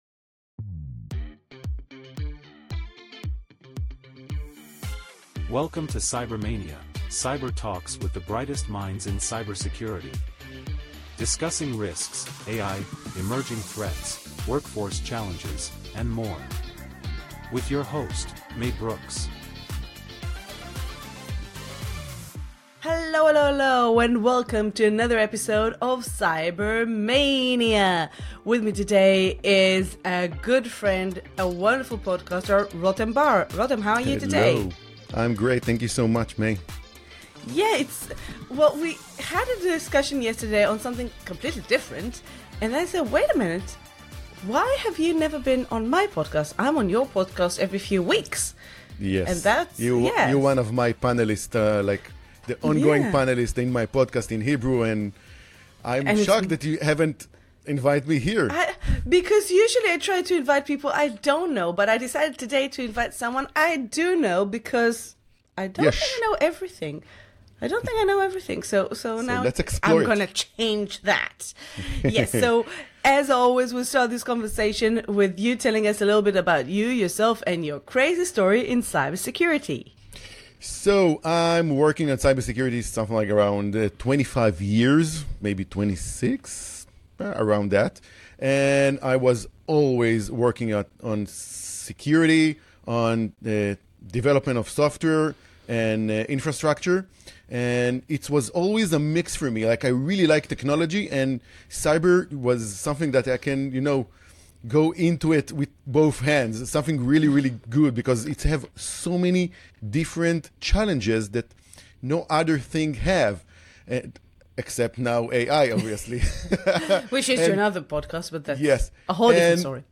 In this episode of CyberMAYnia, I sat down with cybersecurity expert